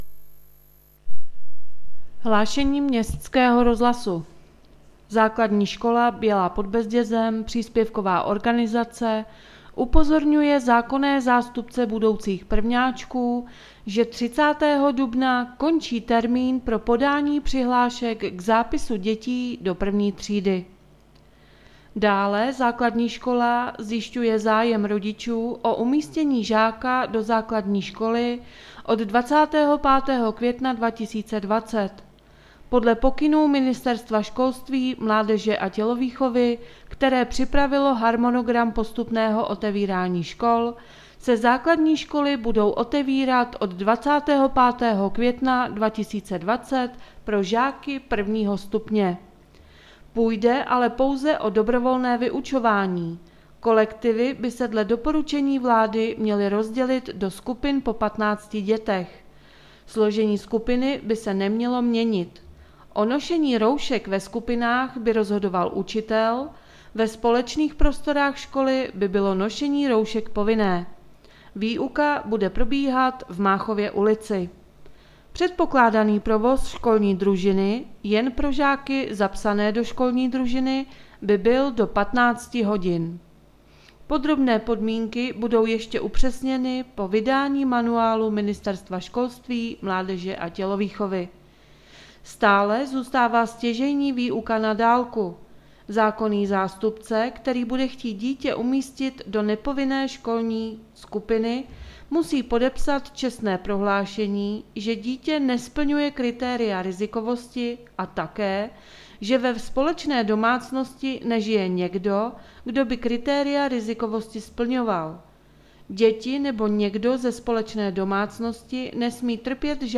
Hlášení městského rozhlasu 24.3.2021